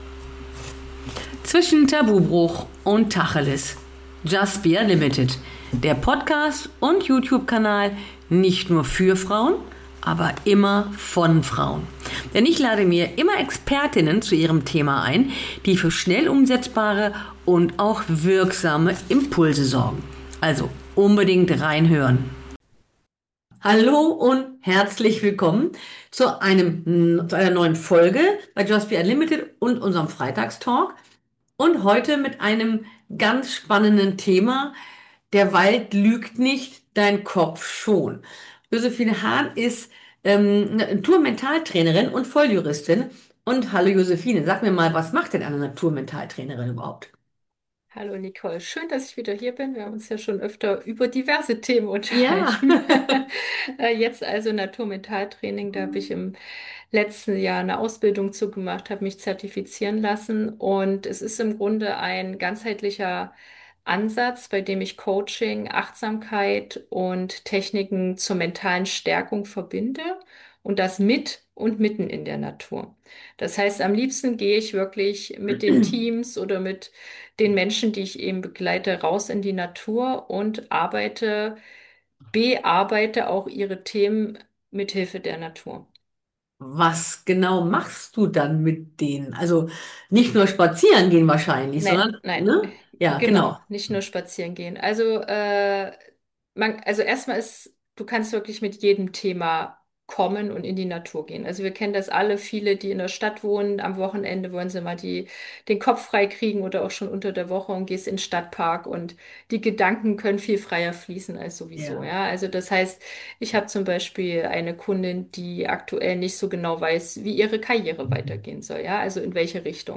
Eher ein ehrliches Gespräch darüber, wie Klarheit überhaupt wieder entstehen kann.